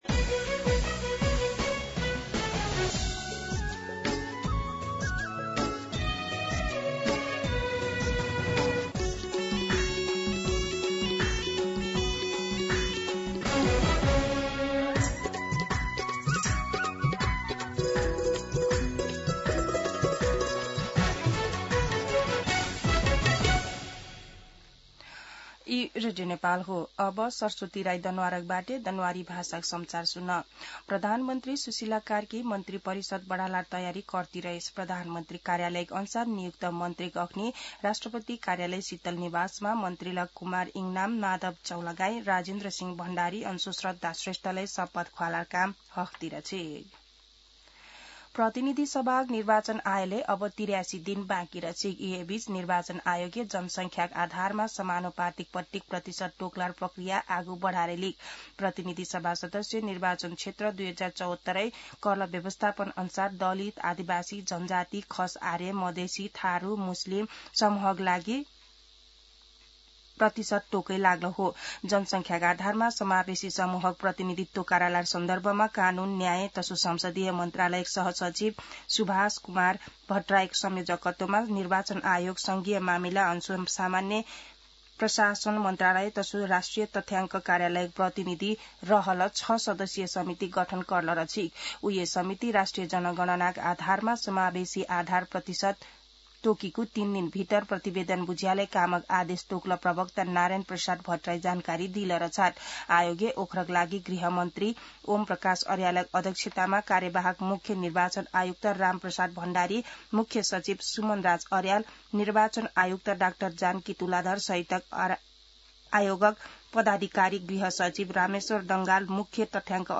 दनुवार भाषामा समाचार : २६ मंसिर , २०८२
Danuwar-News-26.mp3